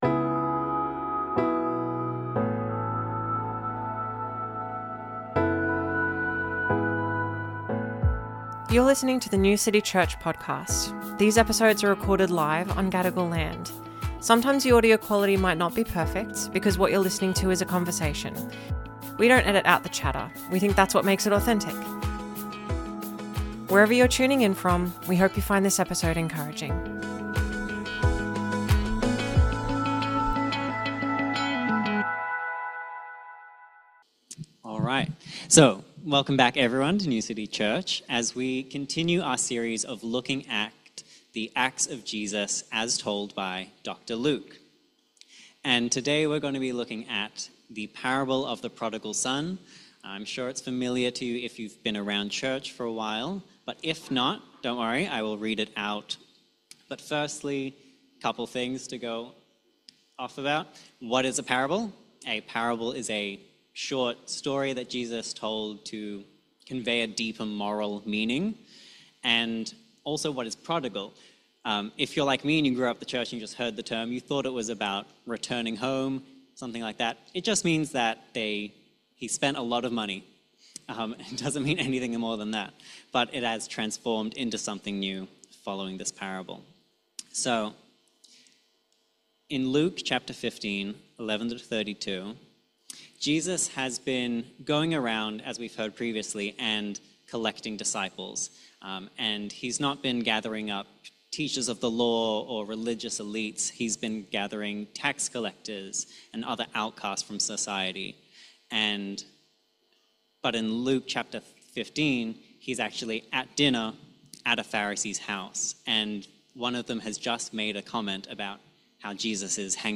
Sermons | New City Church